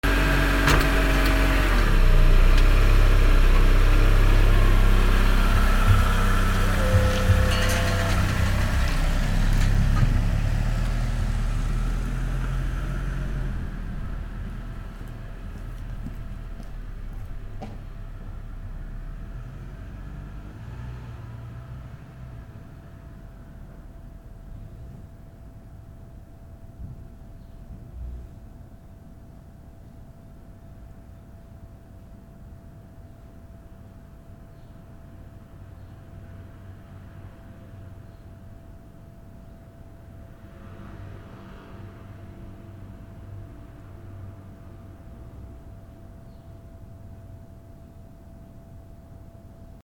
車 車庫から発進
『シューウイーン』